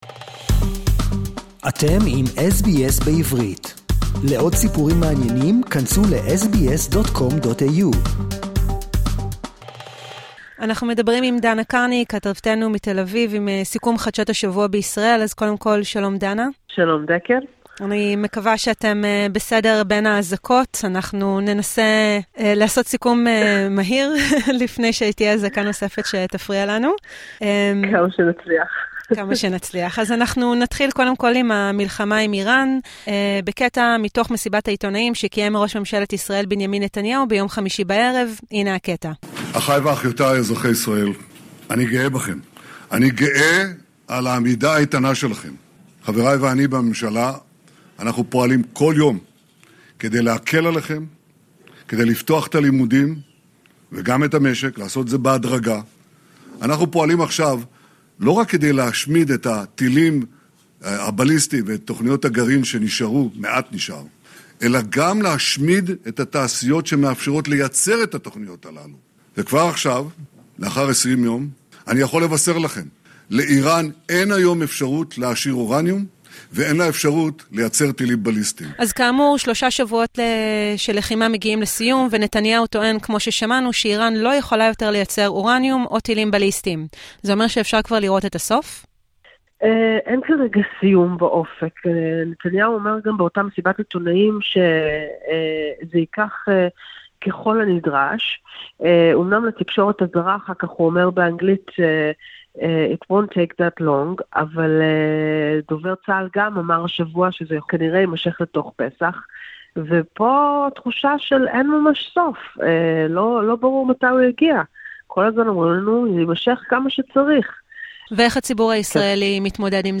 בקול ישראלי ובקצב אוסטרלי.